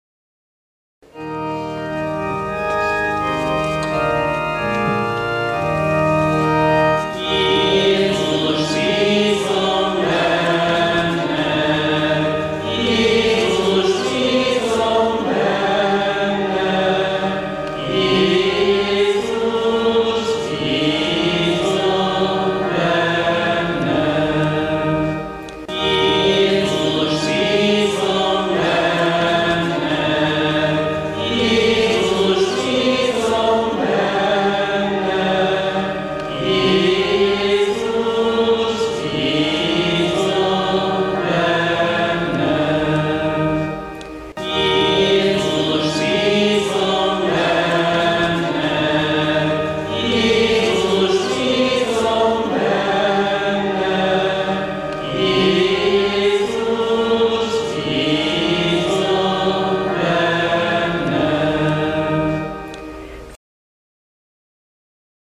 Énekelve az irgalmasság rózsafüzére
tizedek végén énekelhető (Jézus, bízom Benned):